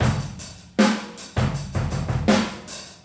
• 79 Bpm Classic Breakbeat B Key.wav
Free drum groove - kick tuned to the B note. Loudest frequency: 1428Hz
79-bpm-classic-breakbeat-b-key-Krk.wav